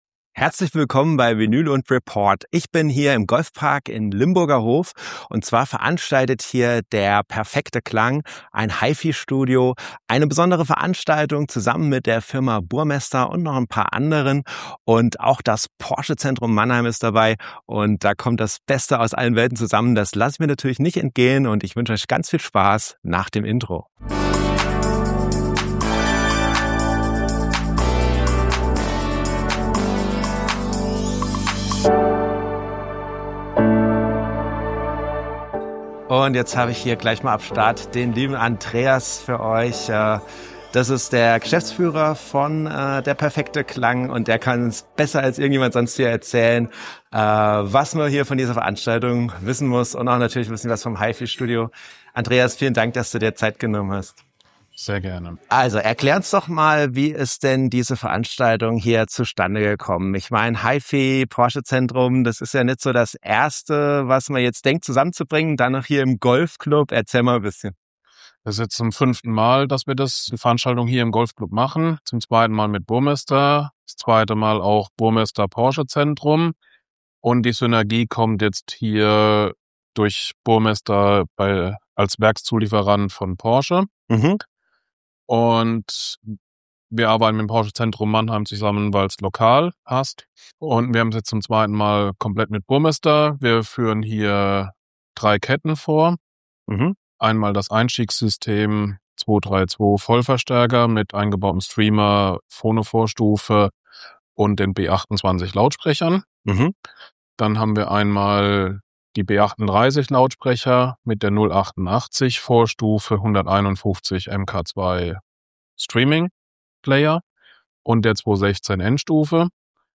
Diesmal bin ich zu Besuch bei Highway to Sound im Golfpark Kurpfalz in Limburgerhof, organisiert von der perfekte Klang aus Dudenhofen.